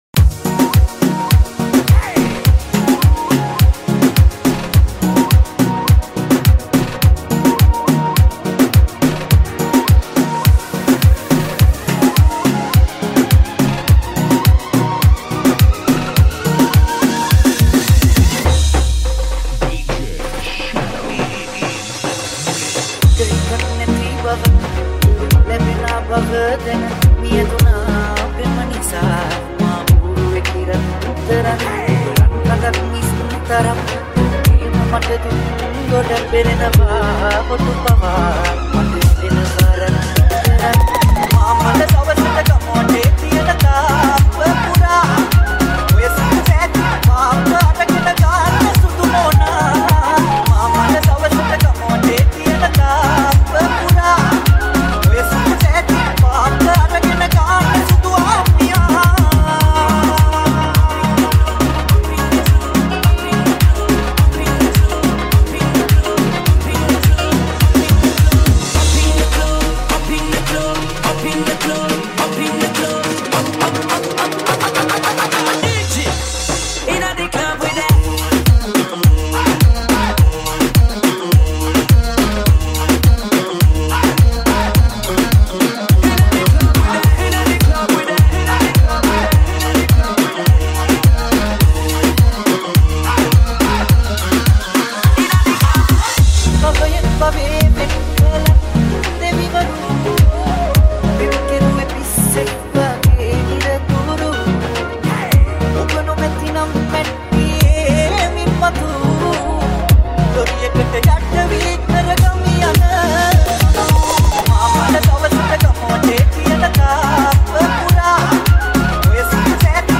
Sinhala Remix
DJ Songs